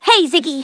synthetic-wakewords
ovos-tts-plugin-deepponies_Twilight Sparkle_en.wav